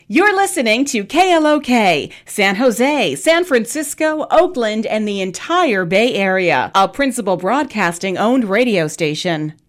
These sound bites capture the energy, warmth, and personality of Bolly92.3 FM, the Bay Area’s first 24/7 Bollywood FM station.
Bolly 92.3 FM – Early Launch Audio Clip (KSJO San Jose, 2016)